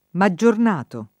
[ ma JJ orn # to ]